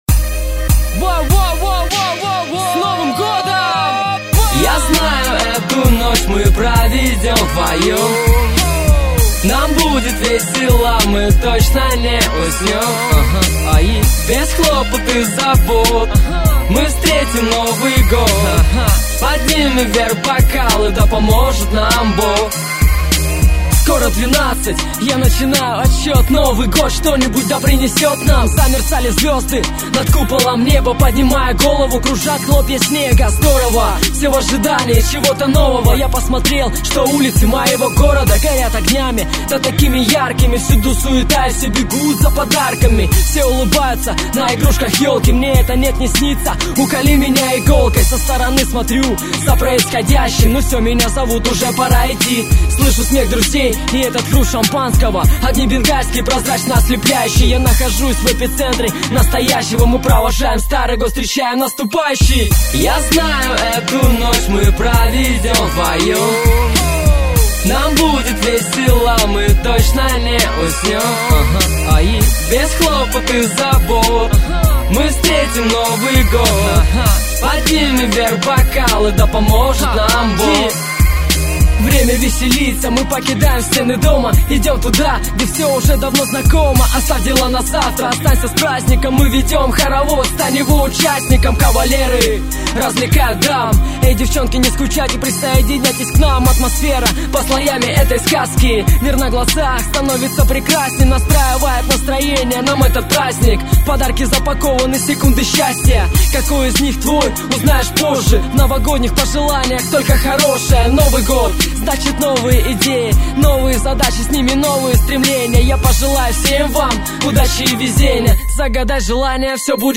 2007 Рэп